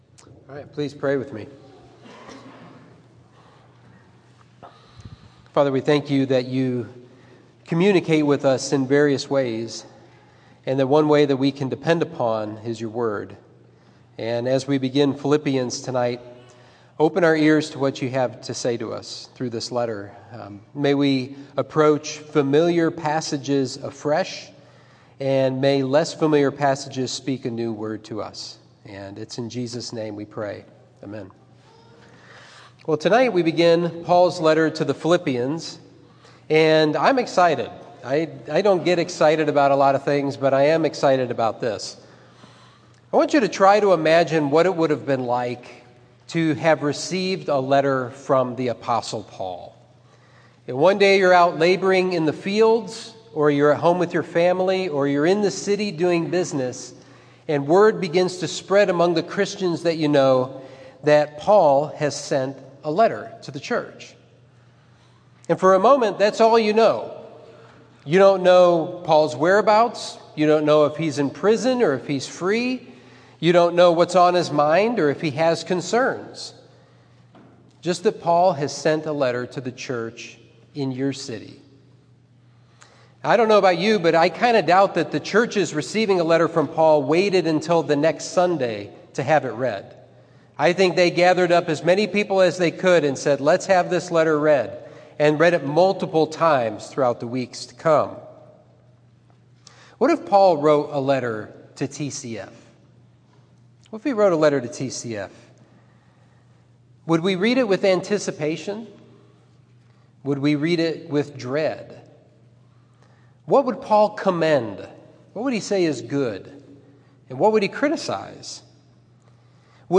Sermon 4/24: Philippians: Soul in the Game – Trinity Christian Fellowship